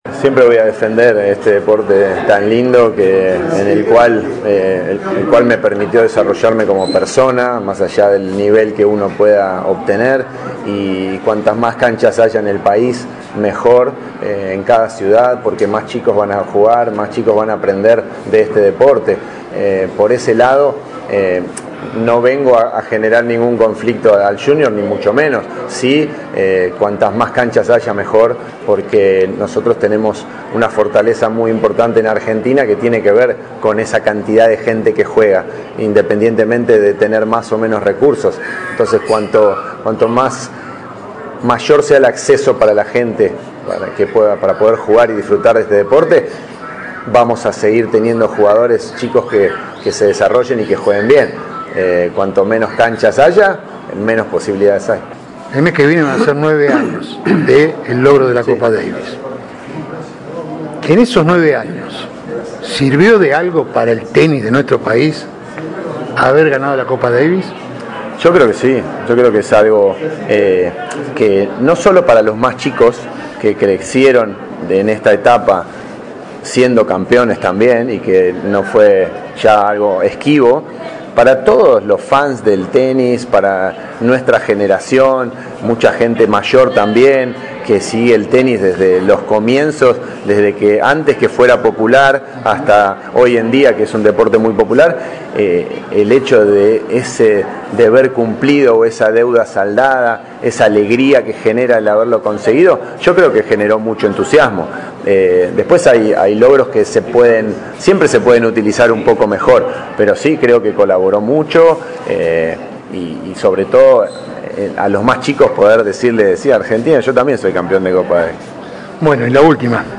Audio de la nota con DANIEL ORSANIC: